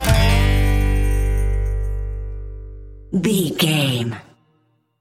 Ionian/Major
acoustic guitar
bass guitar
banjo